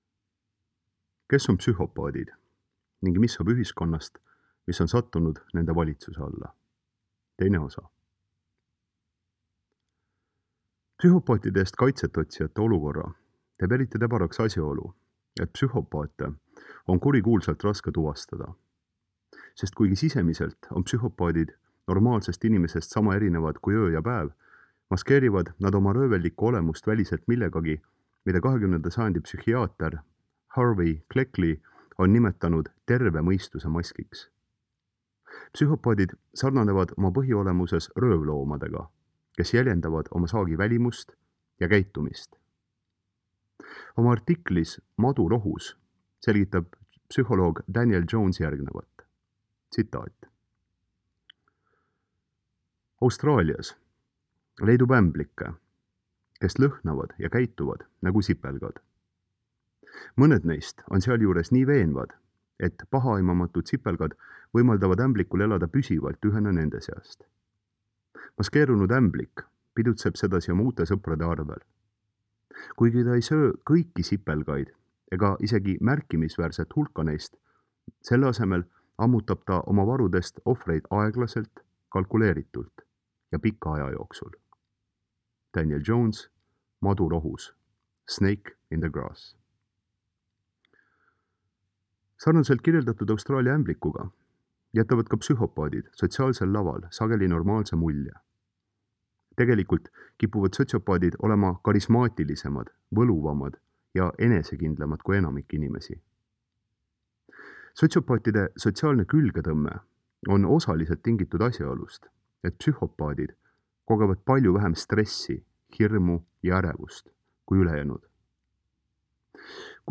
AUDIOVERSIOON kuulatav lehekülje lõpus